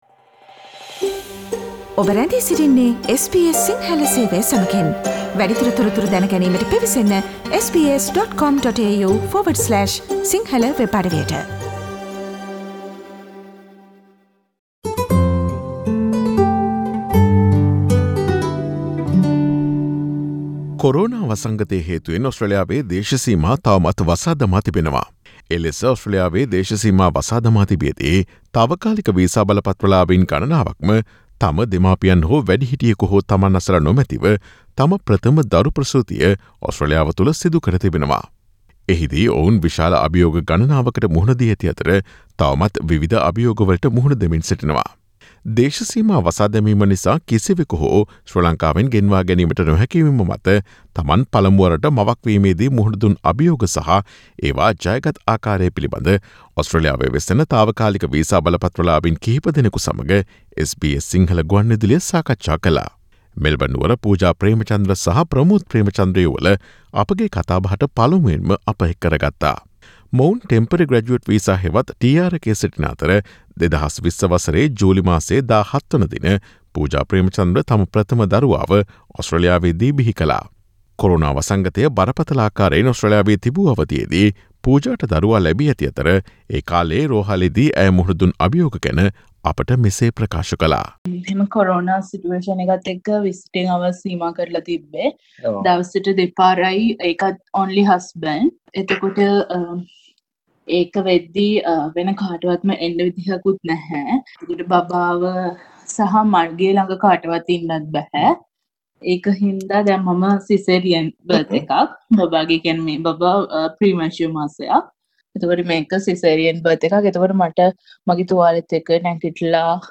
A discussion with Sri Lankans on temporary visas in Australia about their experiences when they became mothers for the first time in their lives while the Australian border closed.